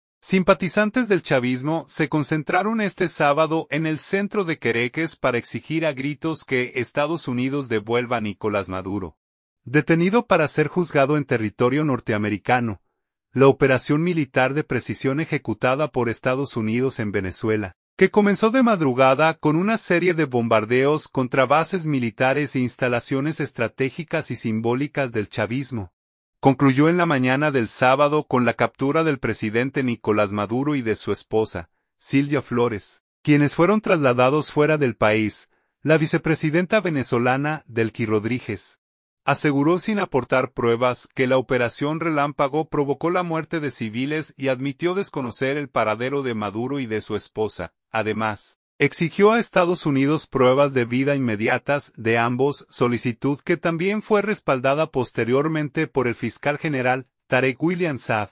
Simpatizantes del chavismo se concentraron este sábado en el centro de Caracas para exigir a gritos que Estados Unidos “devuelva” a Nicolás Maduro, detenido para ser juzgado en territorio norteamericano.